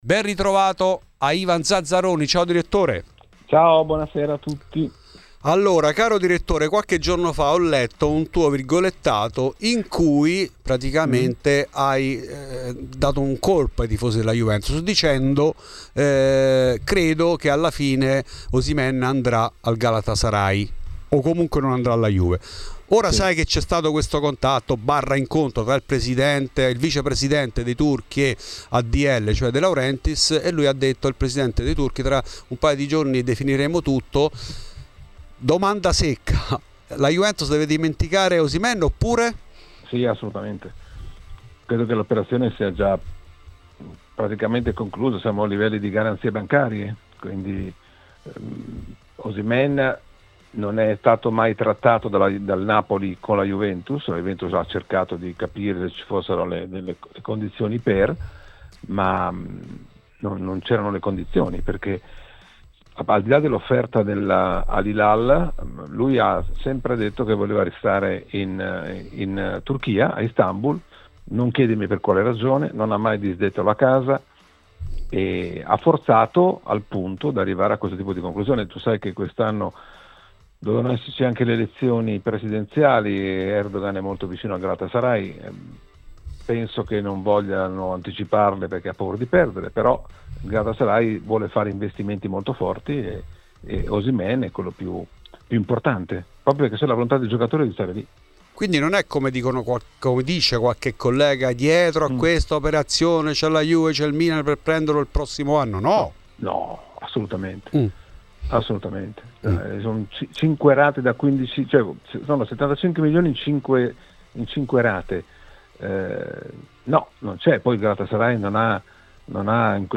In ESCLUSIVA a Fuori di Juve il direttore del Corriere dello Sport Ivan Zazzaroni che chiarisce la sua su Yildiz.